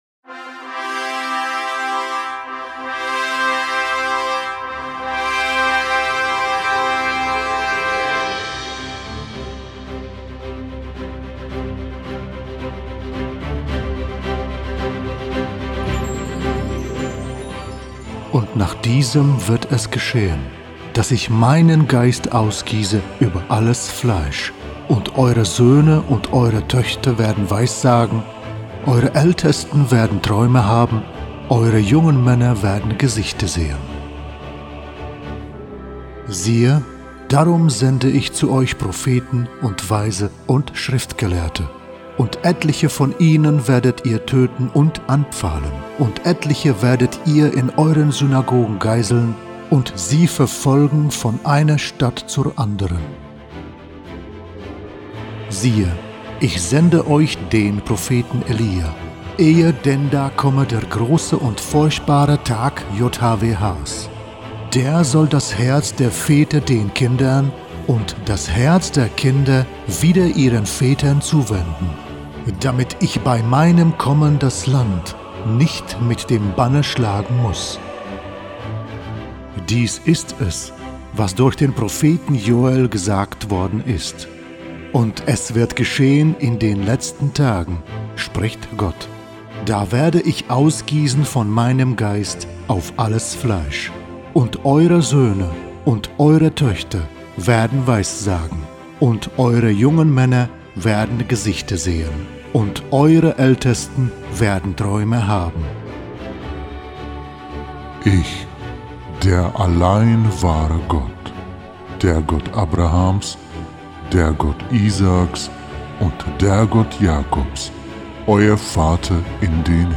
096. Heiliget und liebet das Gesegnete und Heilige als Hörbuch in MP3